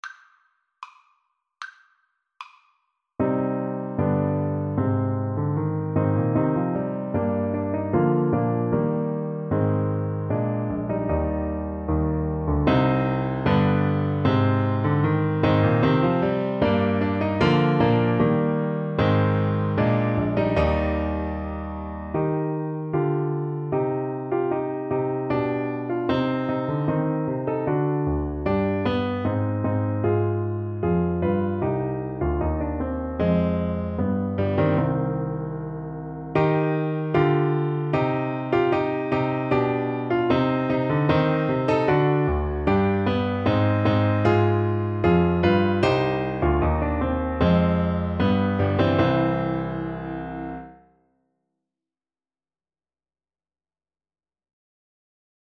Steadily =c.76
2/2 (View more 2/2 Music)
Classical (View more Classical Saxophone Music)